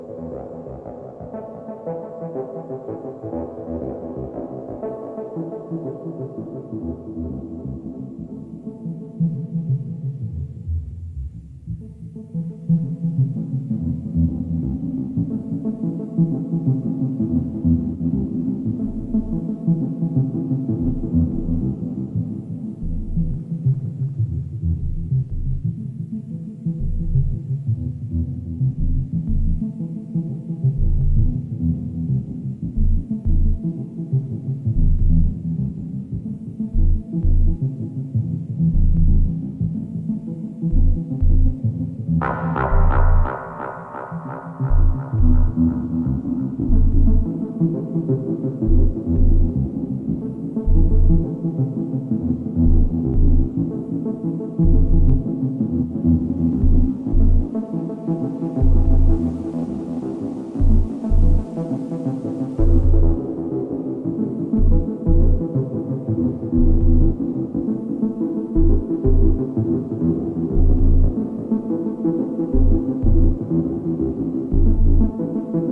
Techno Detroit Dub